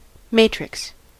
Ääntäminen
US : IPA : [ˈmeɪ.trɪks]